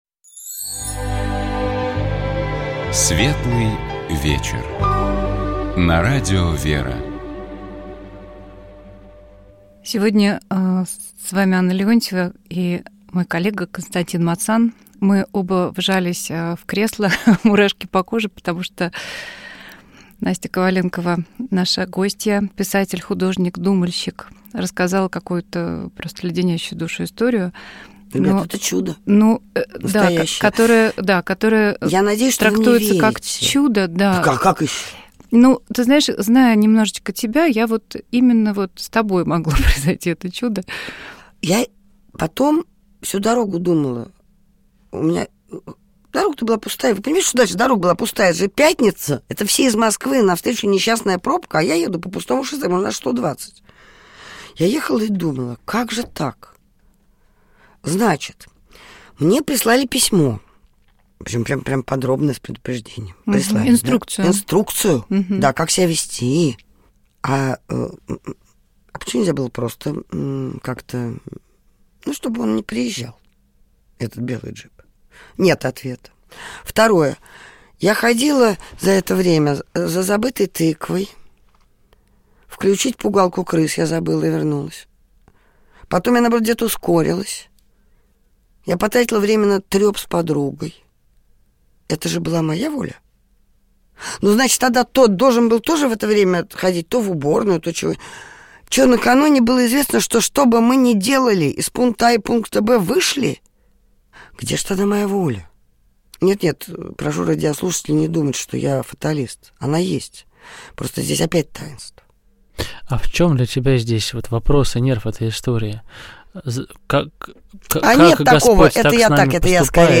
У нас в гостях была писатель, художник, публицист